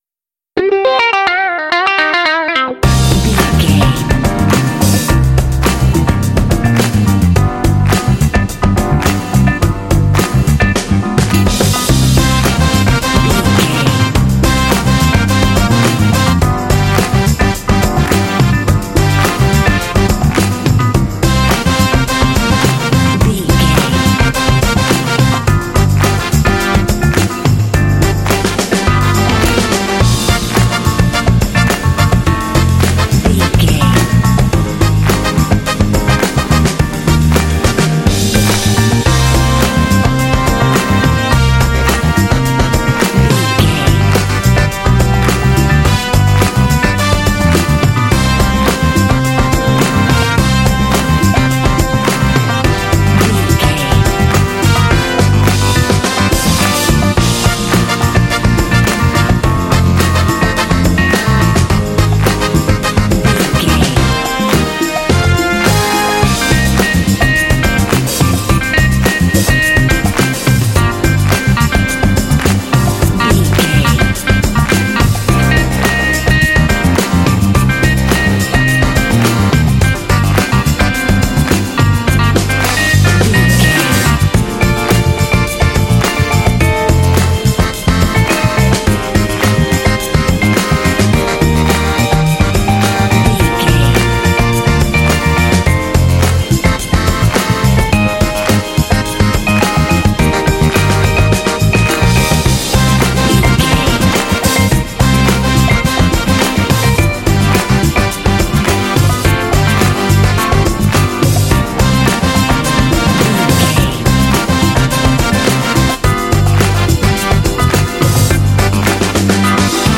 This funky track is ideal for kids and sports games.
Uplifting
Aeolian/Minor
funky
groovy
driving
energetic
lively
bass guitar
drums
percussion
electric piano
saxophone
electric guitar
strings
brass
Funk
alternative funk